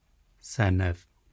The Senedd (/ˈsɛnɛð/